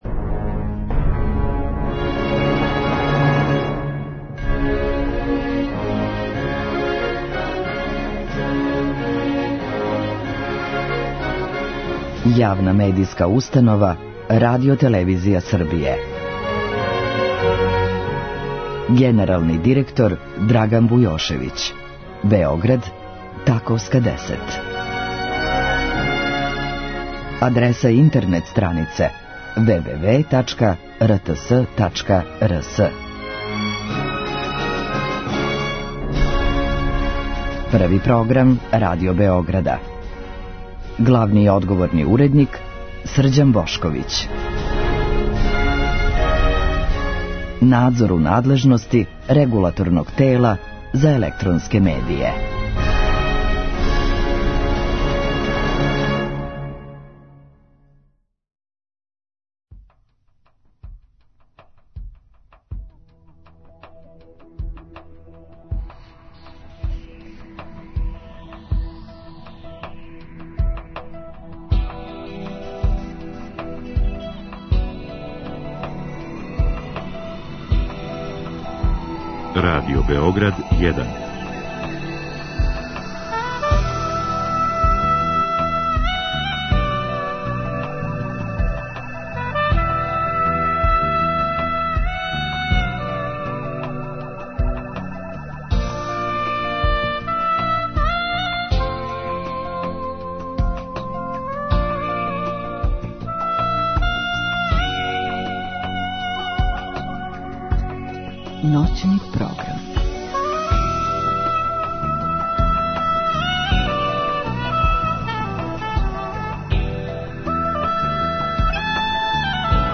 Емисија Шимике и шампите води вас на још једно путовање кроз 50. и 60. године прошлог века. Мало ћемо о цвећу, о књизи Франсоаз Саган 'Добар дан туго', о пролећу које нам куца на врата и све то уз велике хитове који трају, ни време им ништа не може.